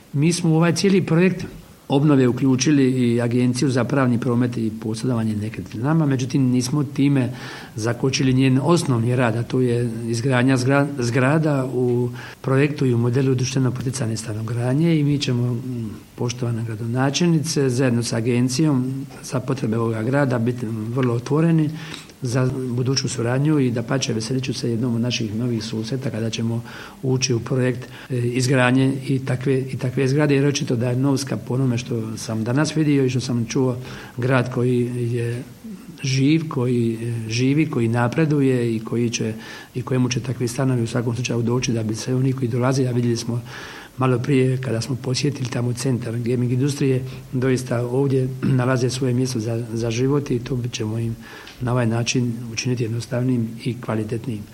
Ministar dodaje